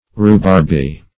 rhubarby - definition of rhubarby - synonyms, pronunciation, spelling from Free Dictionary
rhubarby - definition of rhubarby - synonyms, pronunciation, spelling from Free Dictionary Search Result for " rhubarby" : The Collaborative International Dictionary of English v.0.48: Rhubarby \Rhu"barb*y\, a. Like rhubarb.